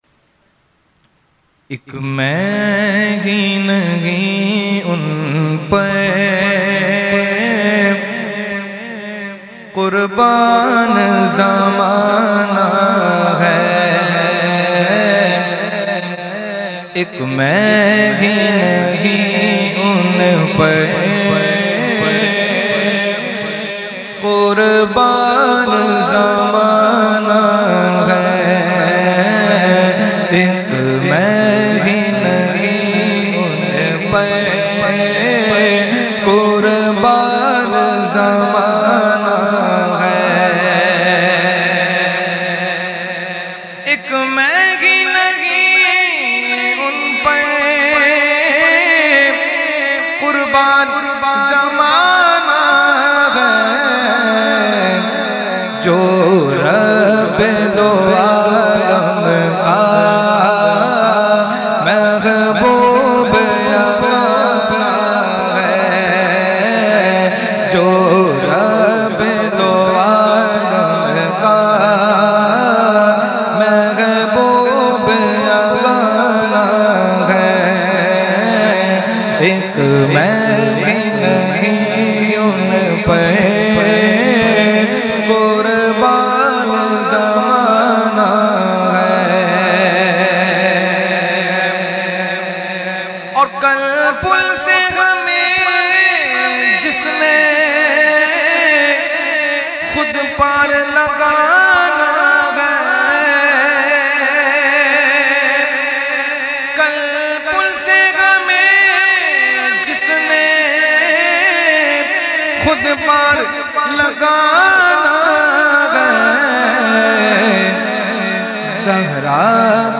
Mehfil e Meelad un Nabi ﷺ Held On 28 October 2020 At Jama Masjid Ameer Hamza Nazimabad Karachi.
Category : Naat | Language : UrduEvent : Mehfil e Milad Jamia Masjid Ameer Hamza 2020